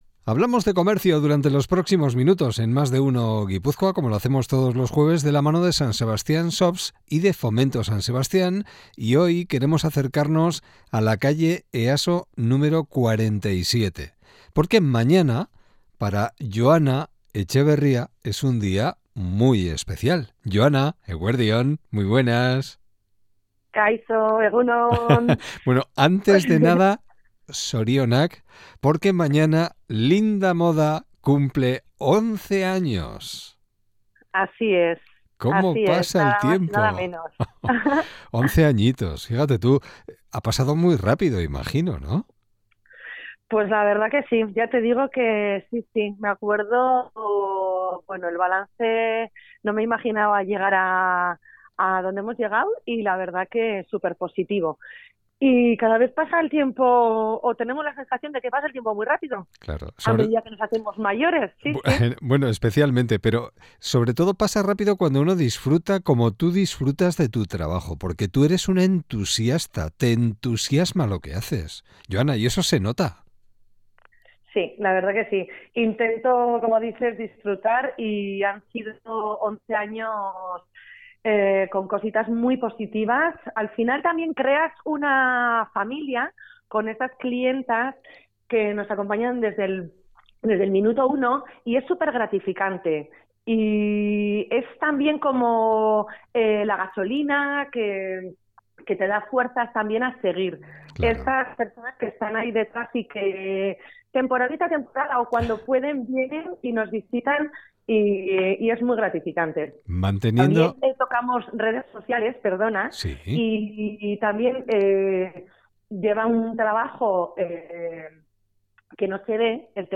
Ez galdu elkarrizketa osoa! https